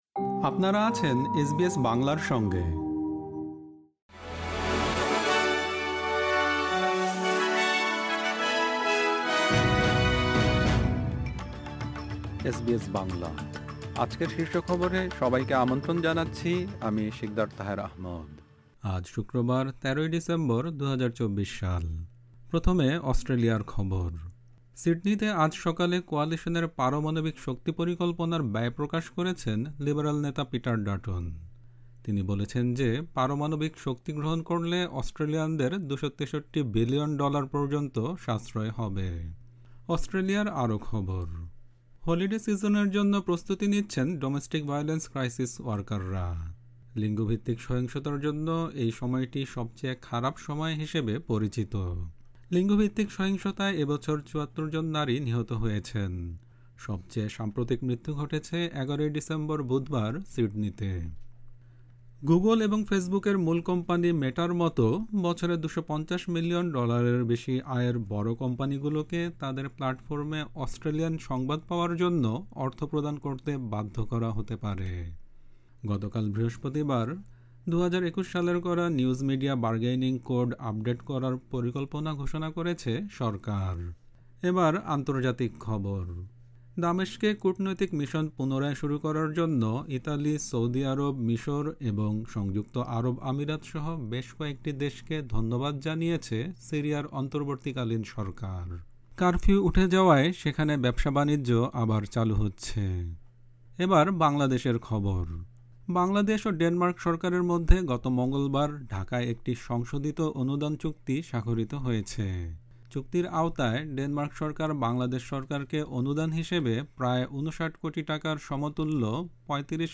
এসবিএস বাংলা শীর্ষ খবর: ১৩ ডিসেম্বর, ২০২৪।